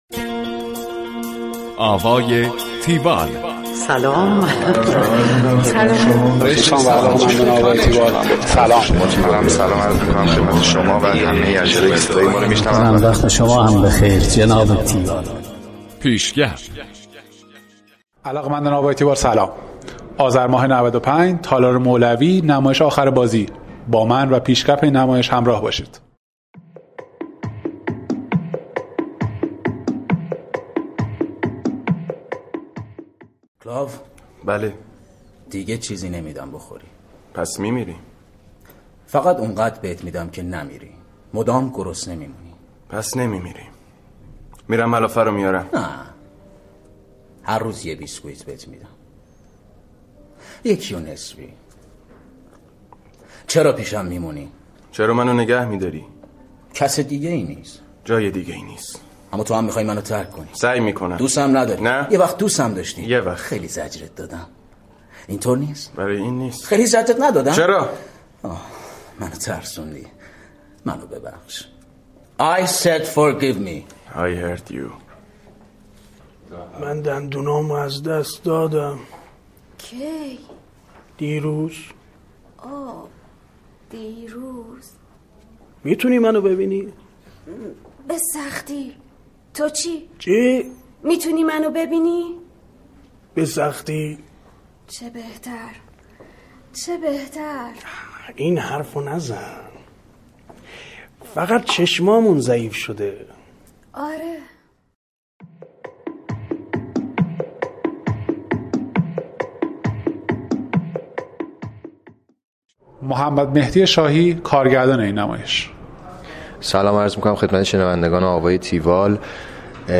گزارش آوای تیوال از نمایش اخر بازی